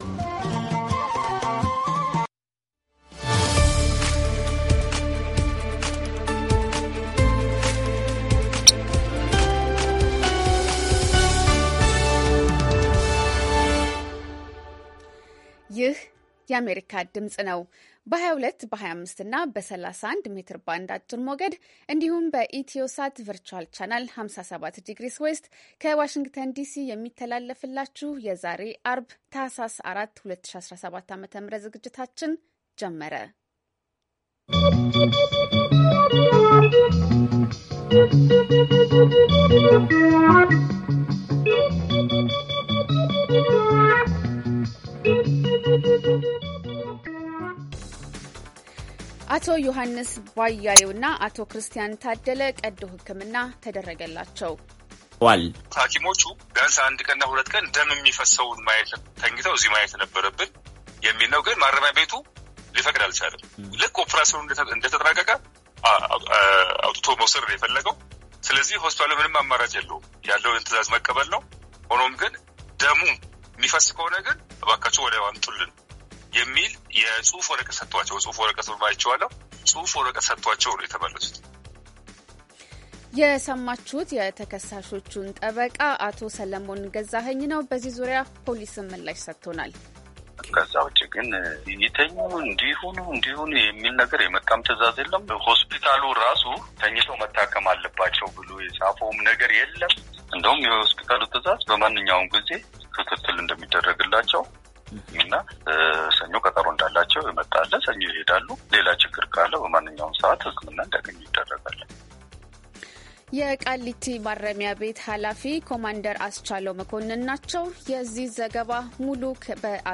ዐርብ፡-ከምሽቱ ሦስት ሰዓት የአማርኛ ዜና